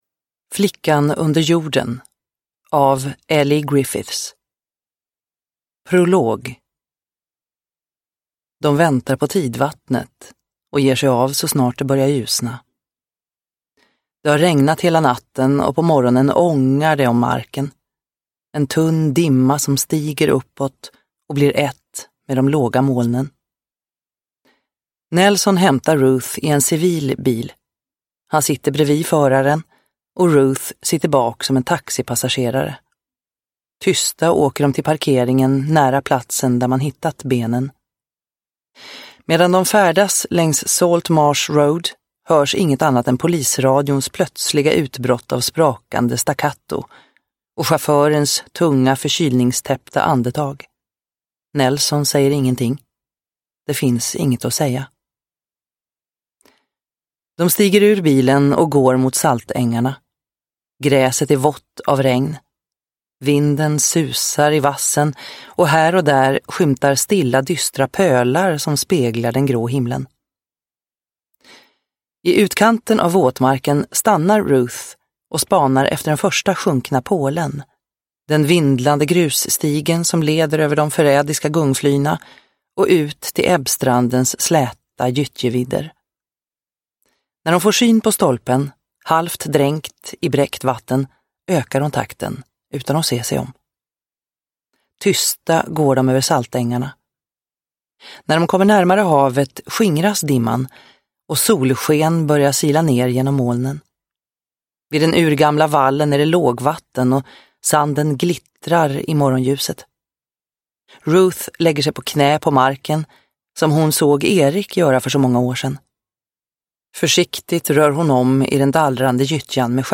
Flickan under jorden – Ljudbok – Laddas ner